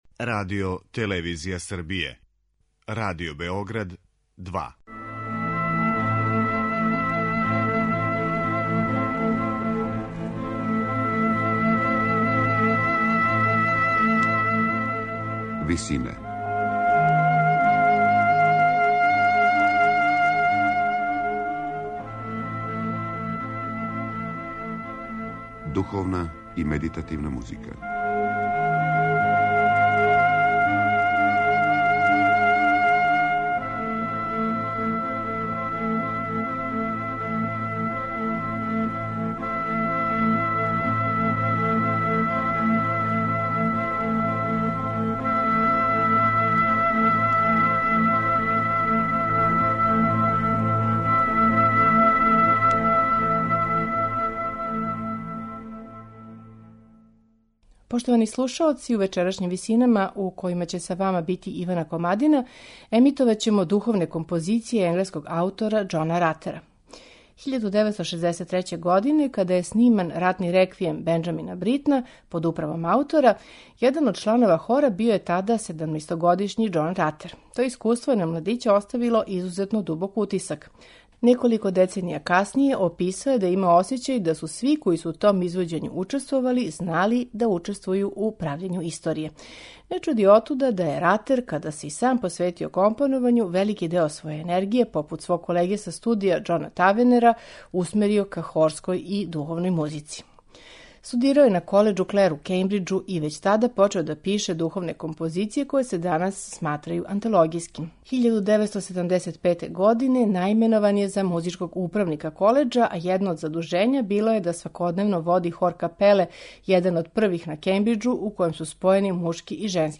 духовне композиције
флаутисткиња
оргуљаши
медитативне и духовне композиције